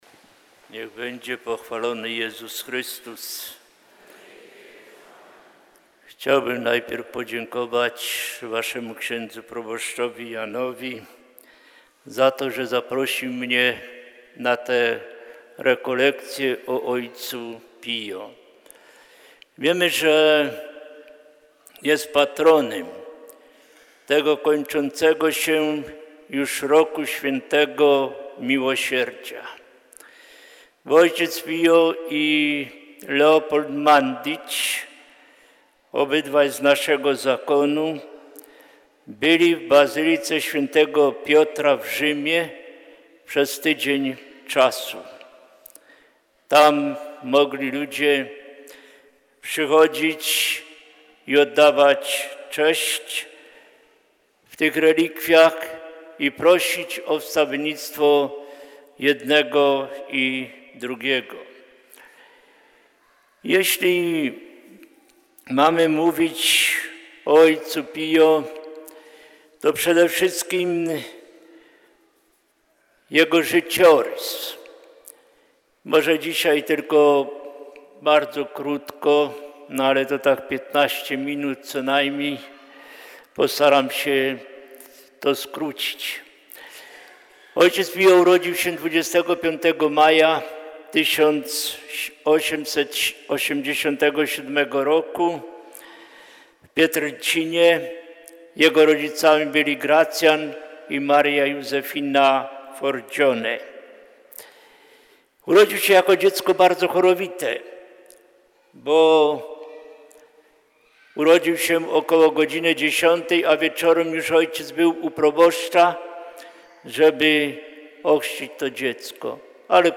Rekolekcje Adwentowe 2016 – relacja audio
Rekolekcje-Adwentowe-2016-Cz1.mp3